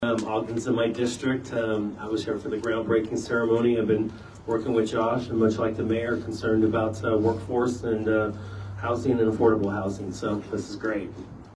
Manhattan Area Habitat for Humanity holds ribbon cutting ceremony for local family
A number of officials attended Thursday’s ribbon cutting including Riley County Commissioner John Ford.